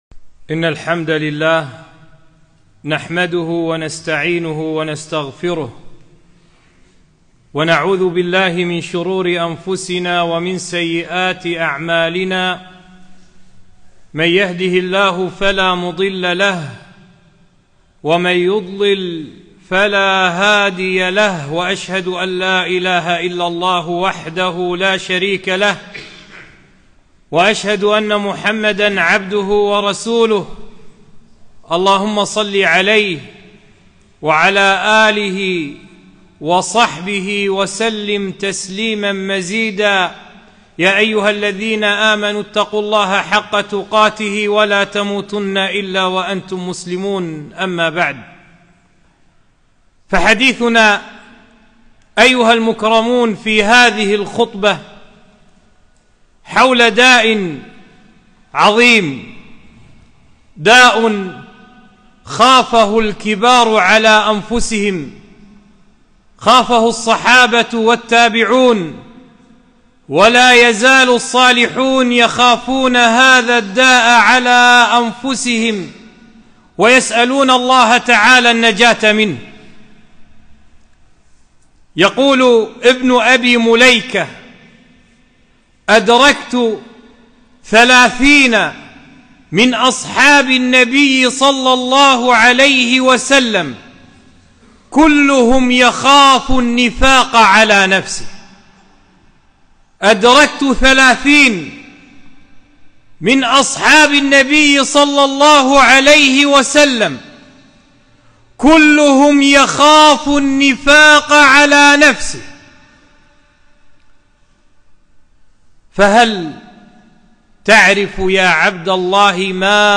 خطبة - خطر النفاق